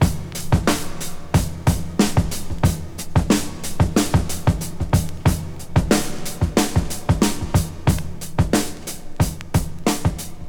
Tuned drums (F key) Free sound effects and audio clips
• 92 Bpm High Quality Breakbeat Sample F Key.wav
Free breakbeat sample - kick tuned to the F note. Loudest frequency: 2181Hz
92-bpm-high-quality-breakbeat-sample-f-key-geq.wav